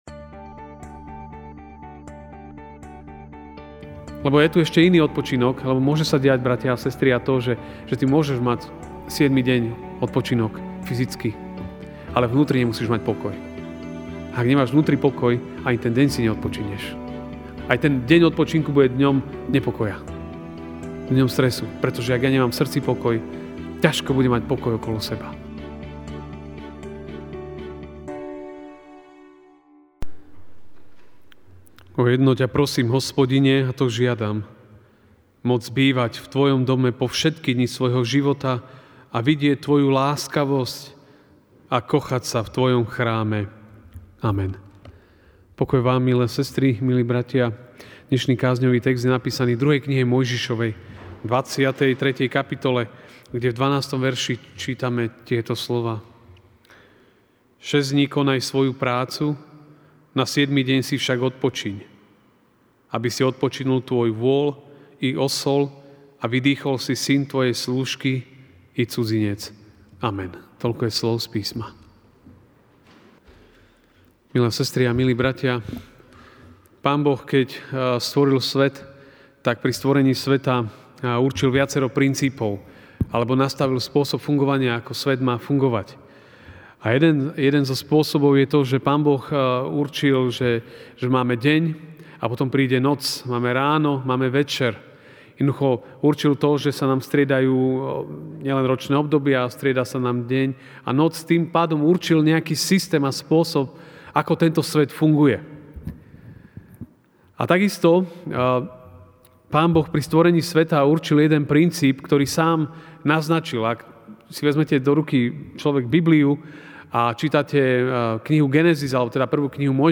jún 23, 2019 Deň odpočinku MP3 SUBSCRIBE on iTunes(Podcast) Notes Sermons in this Series Večerná kázeň: Deň odpočinku (2M 23, 12) Šesť dní konaj svoju prácu, na siedmy deň si však odpočiň, aby si odpočinul tvoj vôl i osol a vydýchol si syn tvojej slúžky i cudzinec.